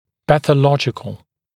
[ˌpæθə’lɔʤɪkl][ˌпэсэ’лоджикл]патологический